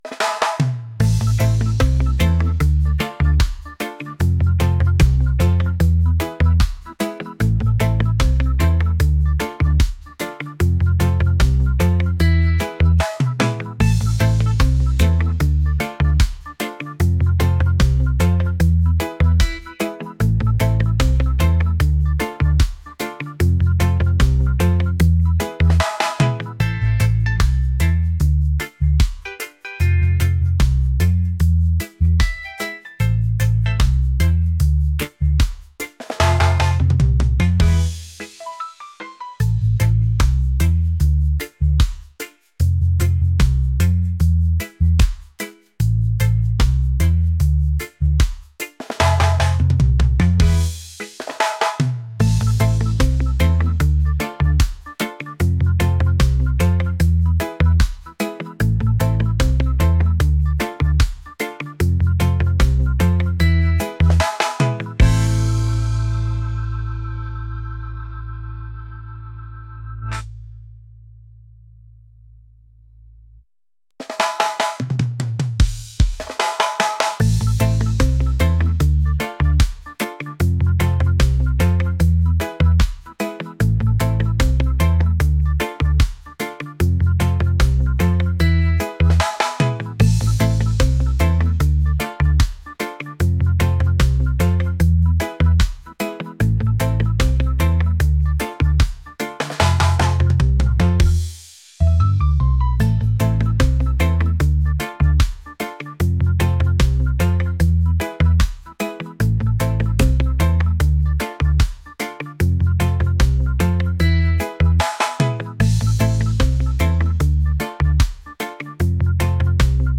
laid-back | groovy | reggae